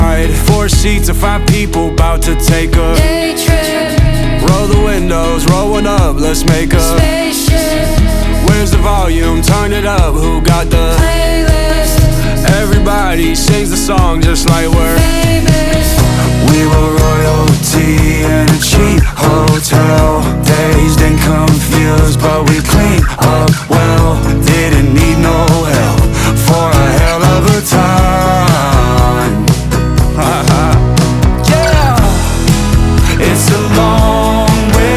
2025-08-08 Жанр: Поп музыка Длительность